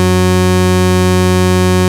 OSCAR 13 F#3.wav